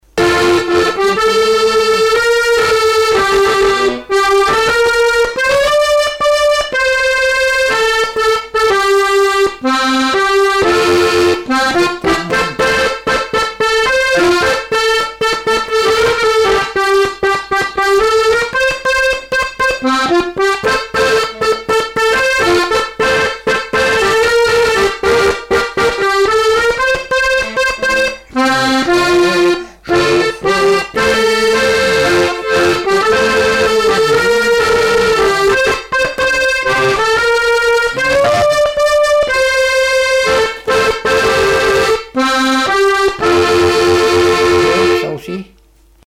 danse : marche
Répertoire du musicien sur accordéon chromatique
Pièce musicale inédite